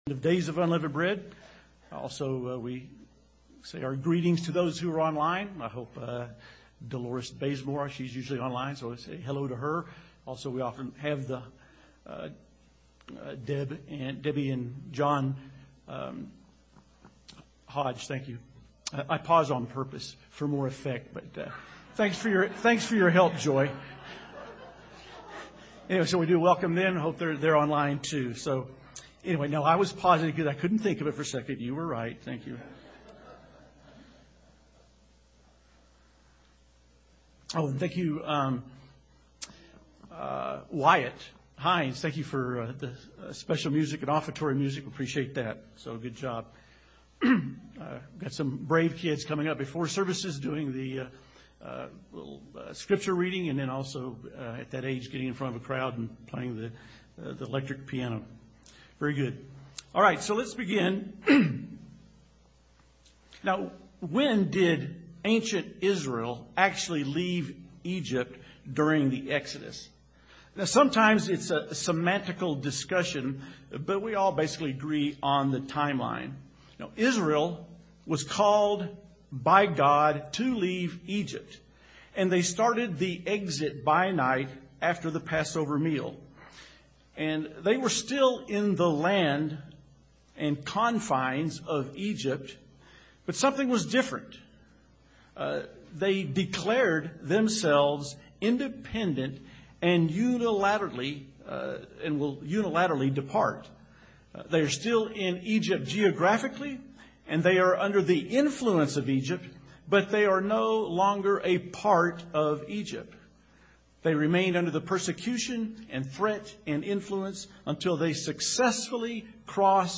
Sermons
Given in Colorado Springs, CO Denver, CO Loveland, CO